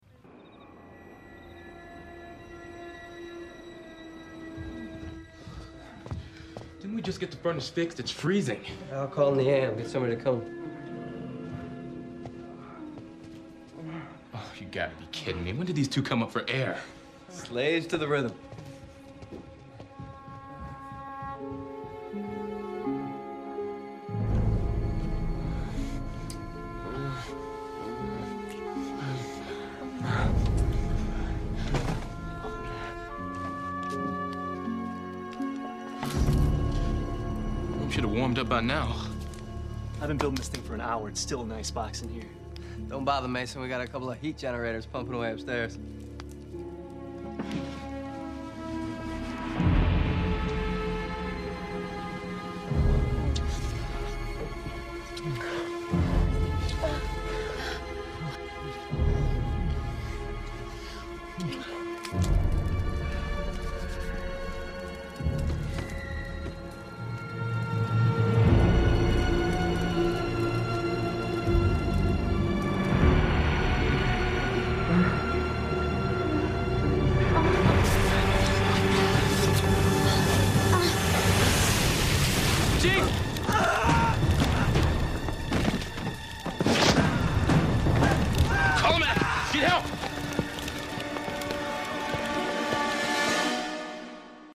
Musique issue de l’album: DVD  rip